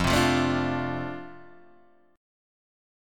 F6 chord